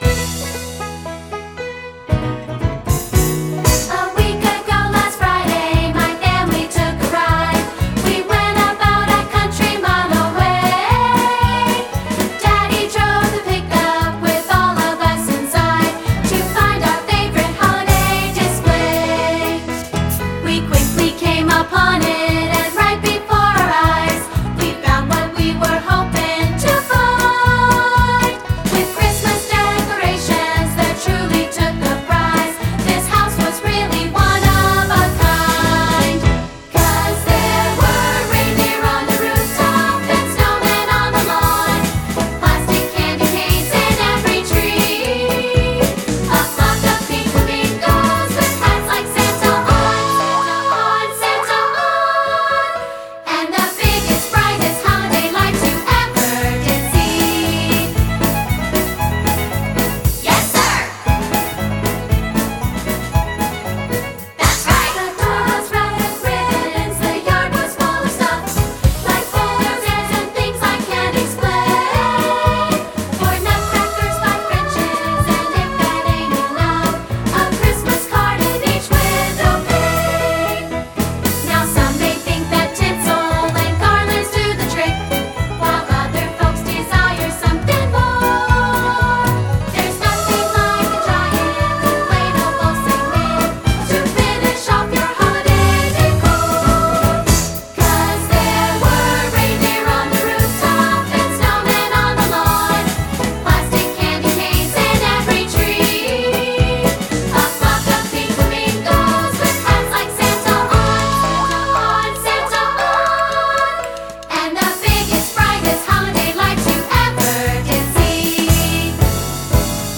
Voicing: 2-Part and Piano